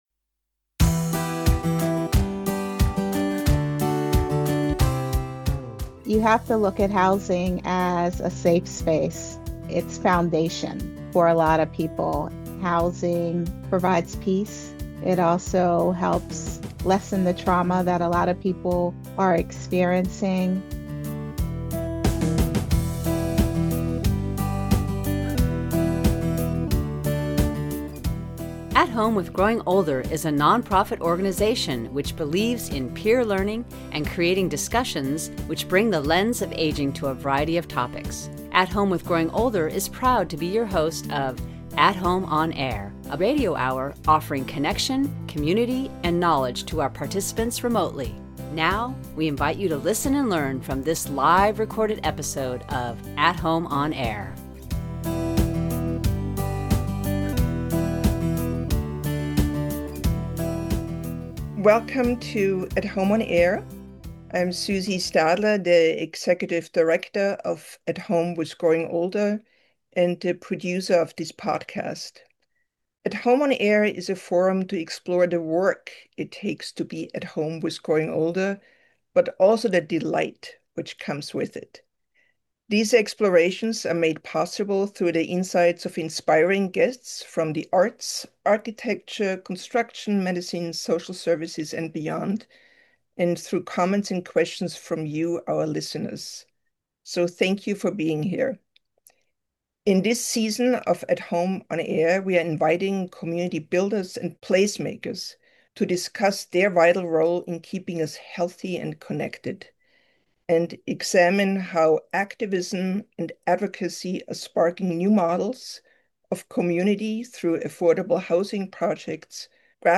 The recording of this conversation has been published as an At Home, On Air podcast episode.